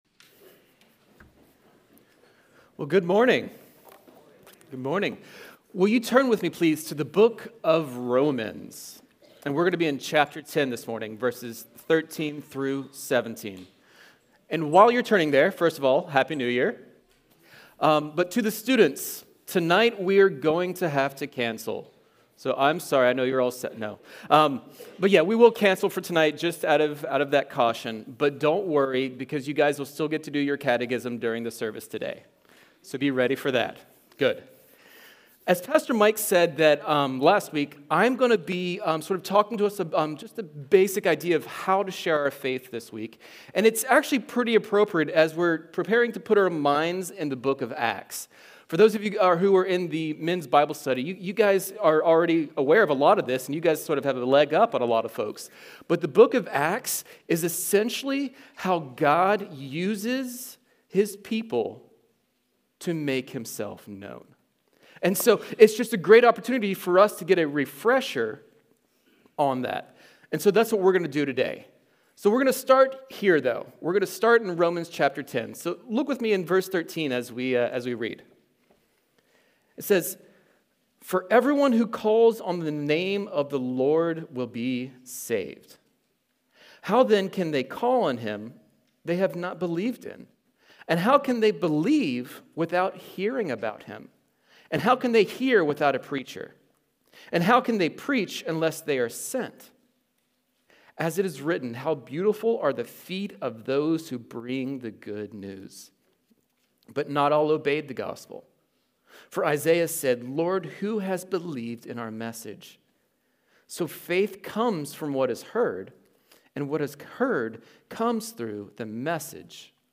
1-5-25-Sunday-Service-.mp3